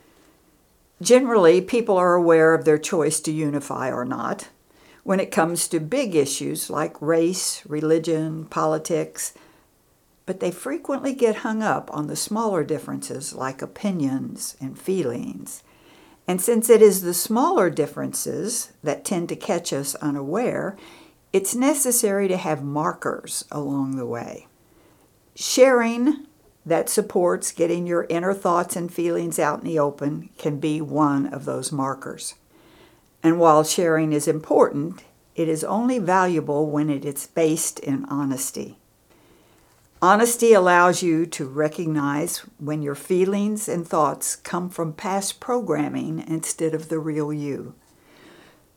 Essential Teachings